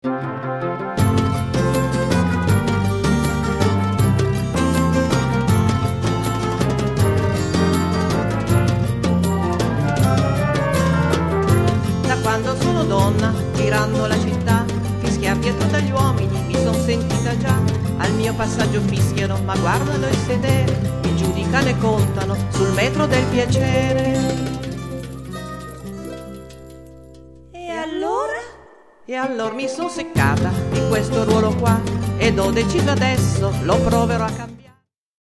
sonorità liriche e orchestrali
ud, darbuka, bandurria...